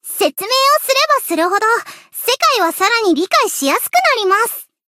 贡献 ） 分类:蔚蓝档案语音 协议:Copyright 您不可以覆盖此文件。